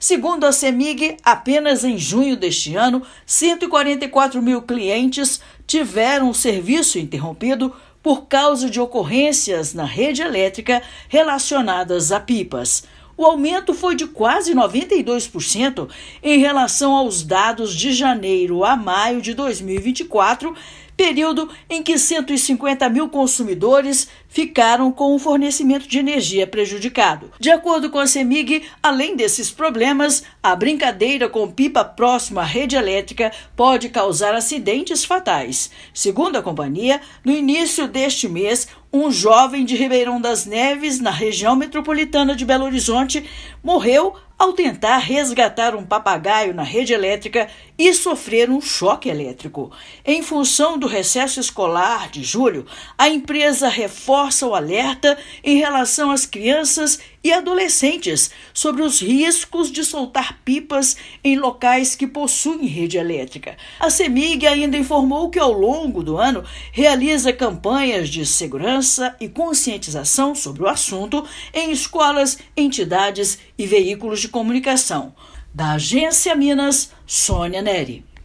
Levantamento da companhia revela aumento significativo no último mês. Ouça matéria de rádio.